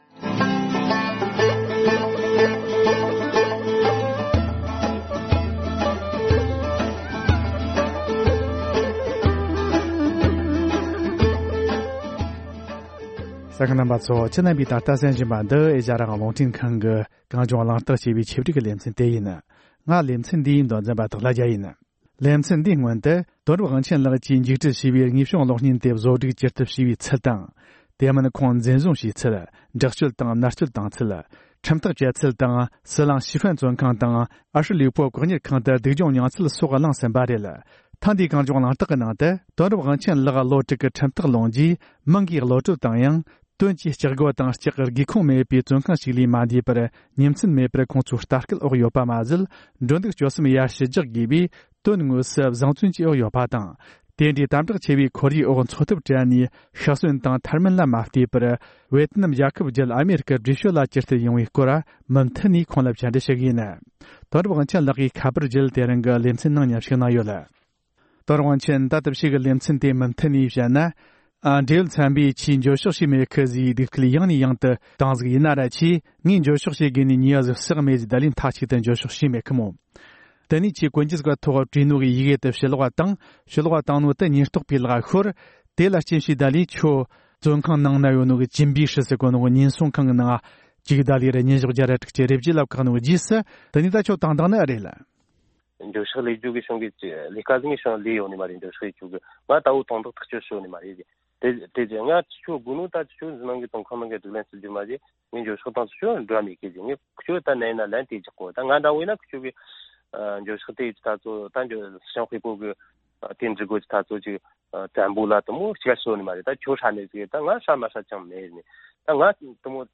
བཅར་འདྲི་ཞུས་པར་གསན་རོགས་གནང་།